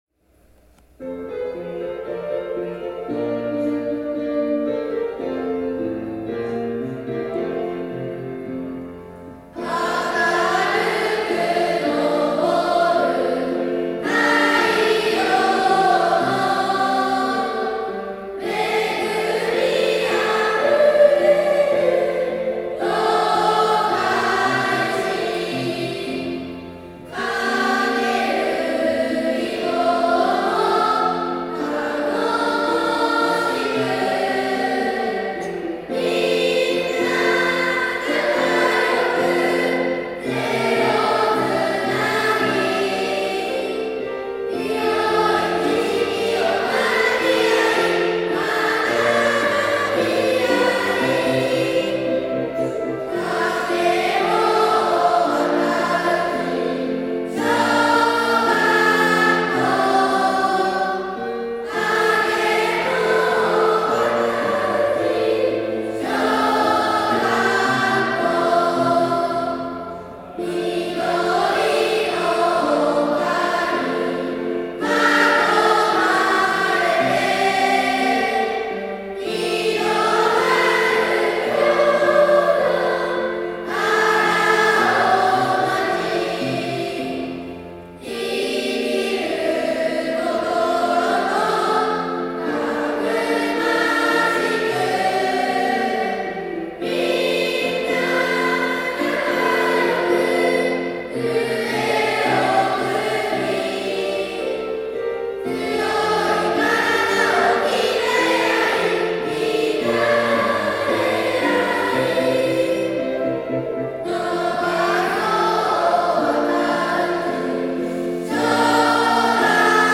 校歌・校章
校歌の再生（令和5年度卒業式）